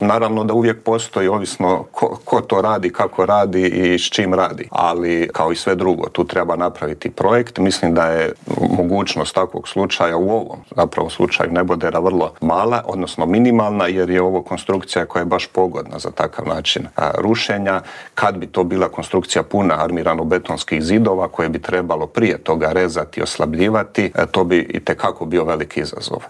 Intervjuu tjedna